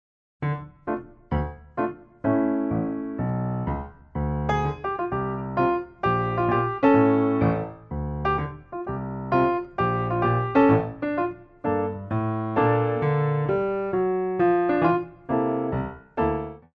By Pianist & Ballet Accompanist
Tendu